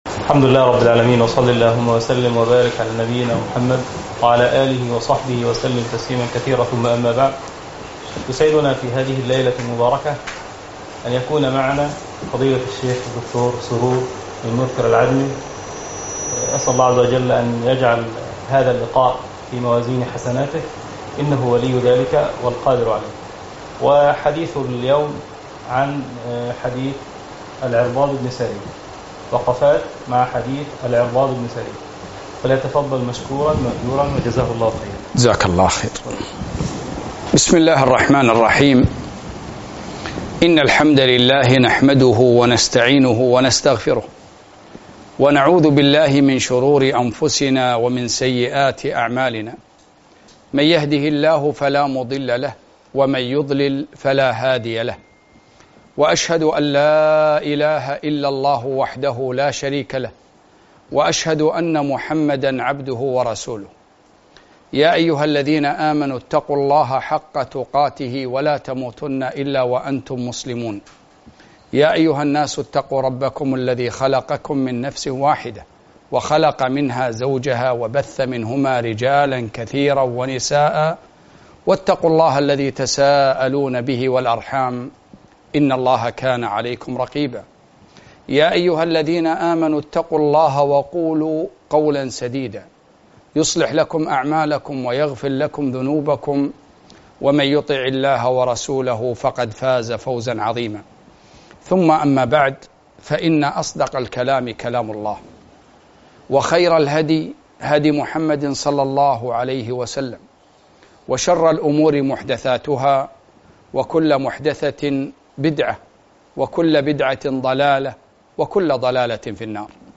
محاضرة - وقفات مع حديث العرباض بن سارية رضي الله عنه